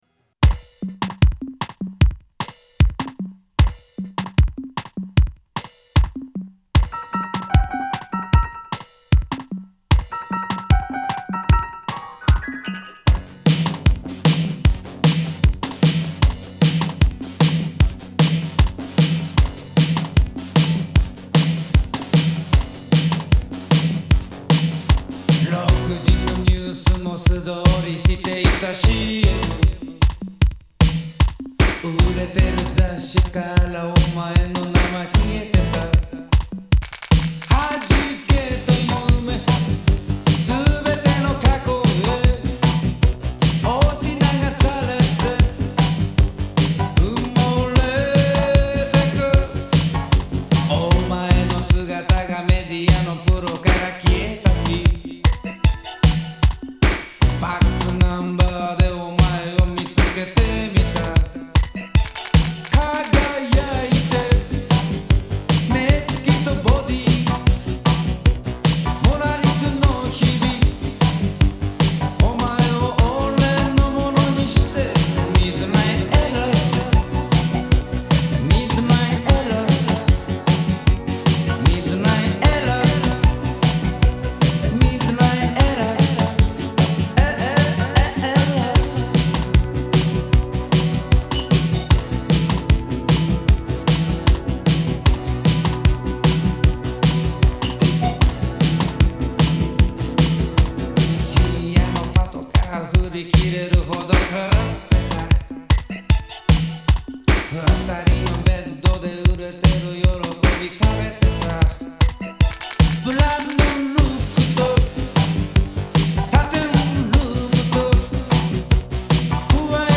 Un morceau absolument fascinant de Hard fm.